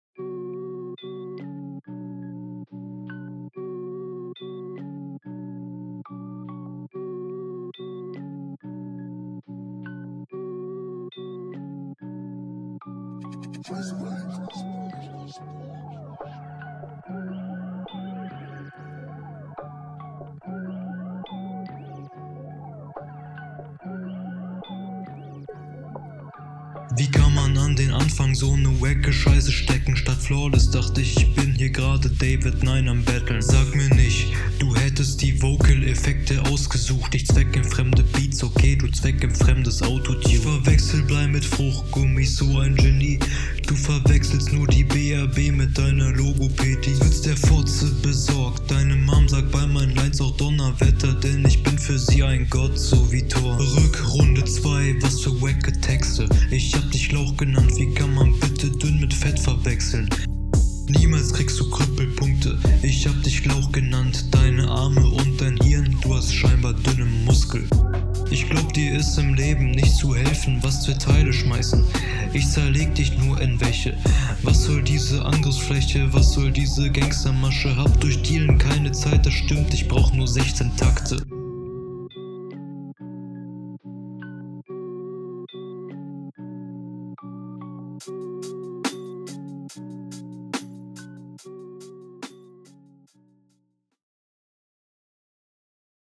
guter sound, mag ich. am ende des parts flowlich bisschen raus zwischendurch, aber nice
Bei Hörgenuss 3 Punkte Abzug wegen dem dauerhaften Autotune Effekt. ich hatte legit Schmerzen beim …